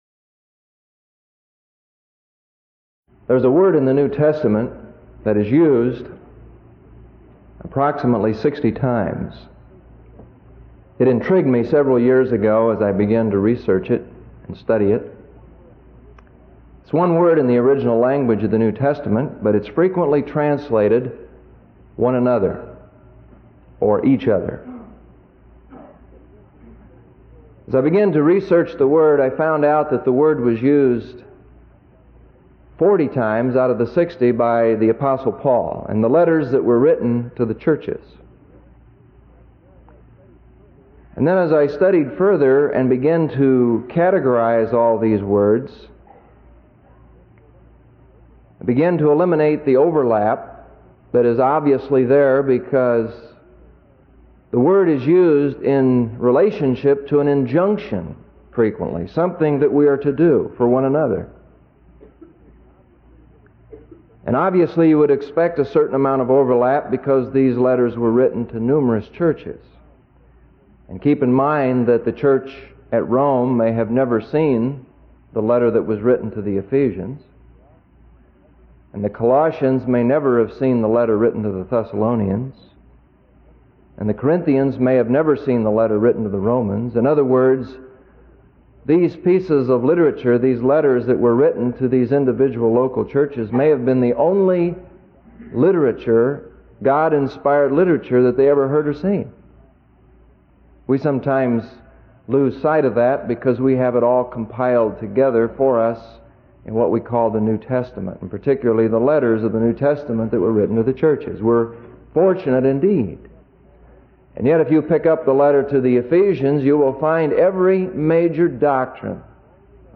BBNSermons Sermon